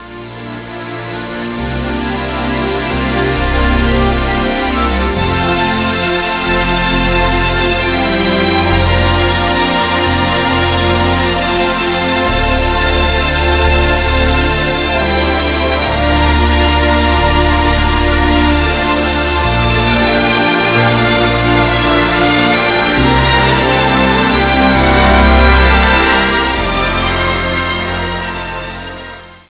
nuovo score basato su musica pop